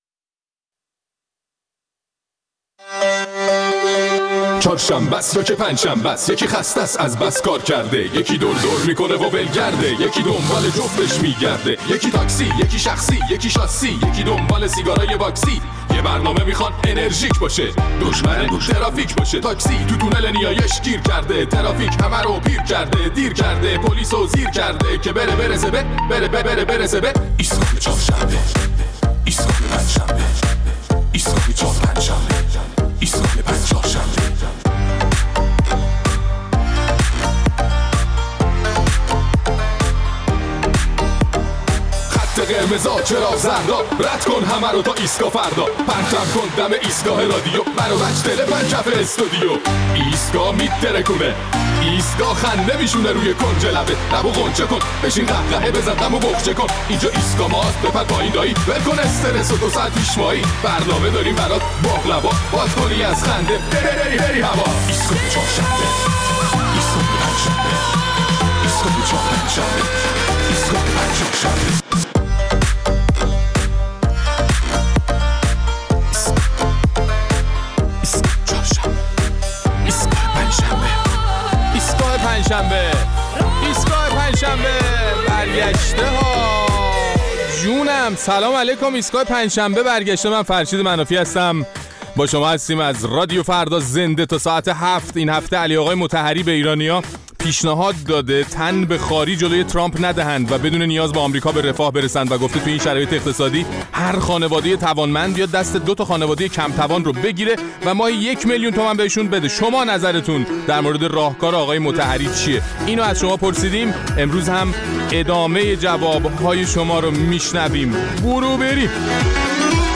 در این برنامه ادامه واکنش‌های مخاطبان ایستگاه فردا را به پیشنهاد علی مطهری در مورد کمک خانواده‌های برخوردار به خانواده‌های نیازمند برای گذراندن دوران تحریم می‌شنویم.